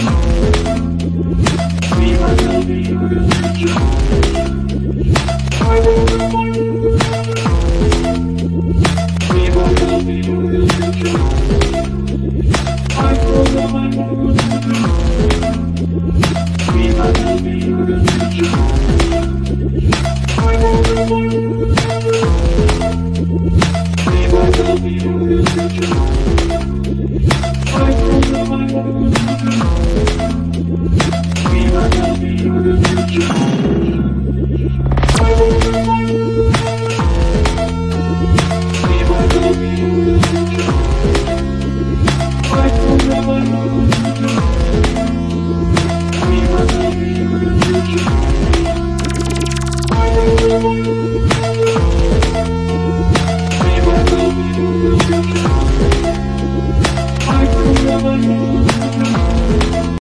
Hi-tech disco/electro/techno/pop?!